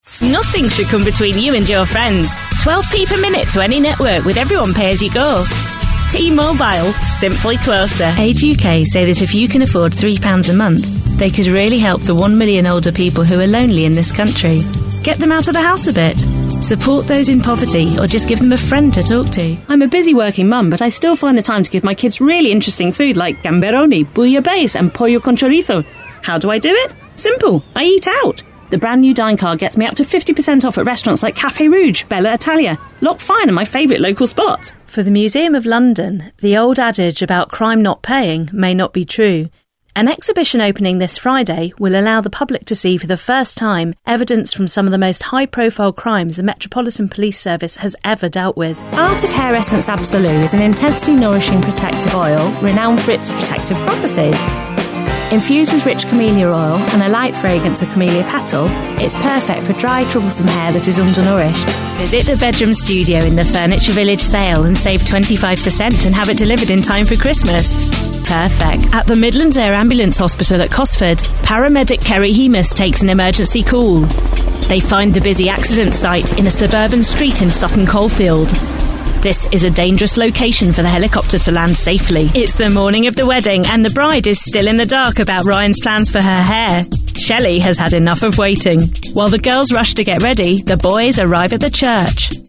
Female / 30s, 40s / English / Northern
Showreel